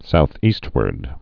(south-ēstwərd, sou-ēst-)